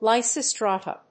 /ˌlaɪsɪˈstrɑtʌ(米国英語), ˌlaɪsɪˈstrɑ:tʌ(英国英語)/